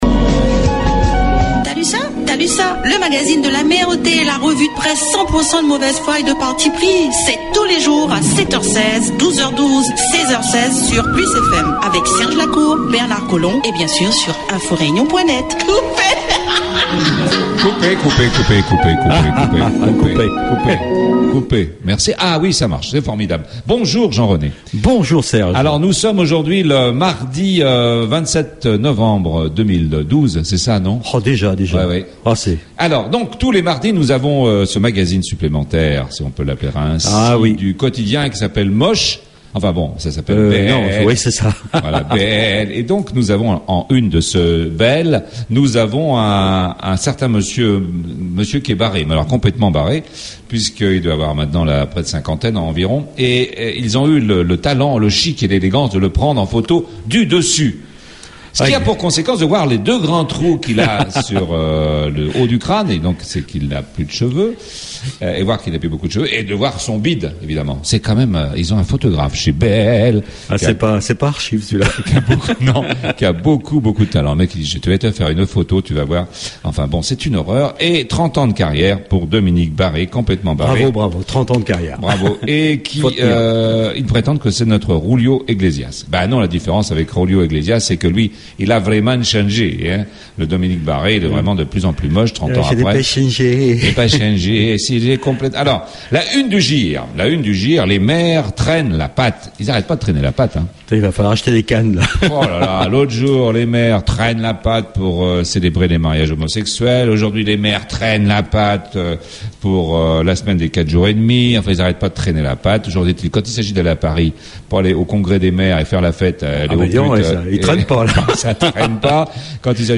La Revue de Presse la plus drôle, la moins sérieuse, la plus décalée, la moins politique, et la plus écoutée sur PLUS FM 100.6 dans le Nord, et 90.4 dans l'Ouest...